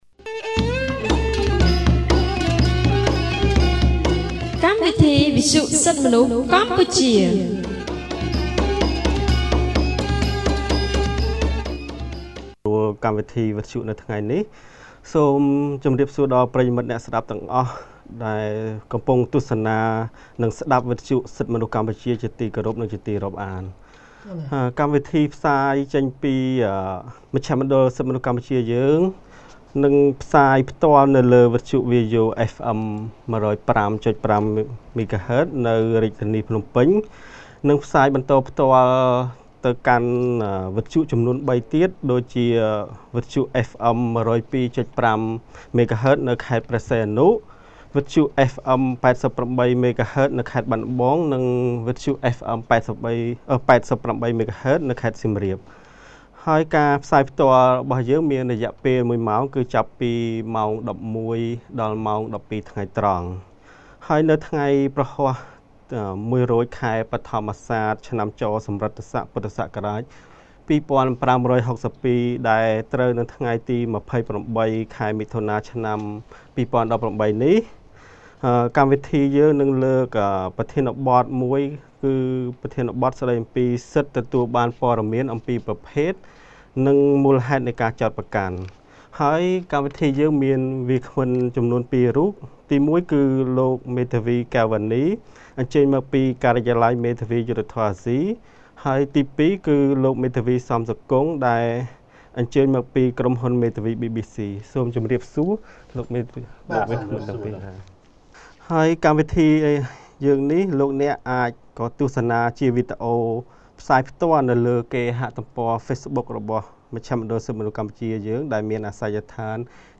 On 28 June 2018, CCHR’s Fair Trial Rights Project (FTRP) held a radio program with a topic on Right to be Informed of the Nature and Cause of the Charge.